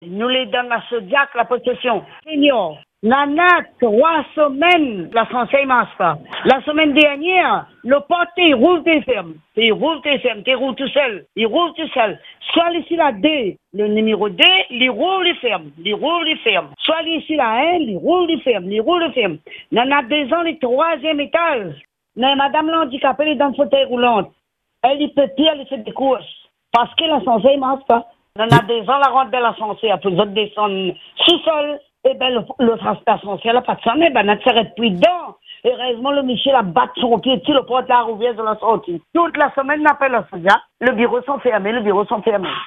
Une habitante témoigne des difficultés quotidiennes et de l’urgence d’une intervention pour que les seniors puissent retrouver leur autonomie.